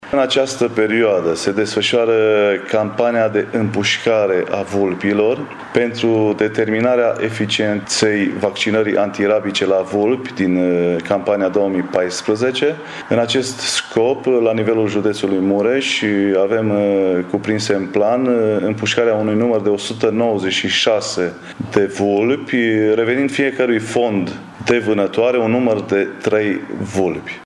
Specialiştii urmăresc efectul vaccinării antirabice, spune şeful Direcţiei Sanitar-Veterinare şi pentru Siguranţa Alimentelor Mureş, Liviu Oprea: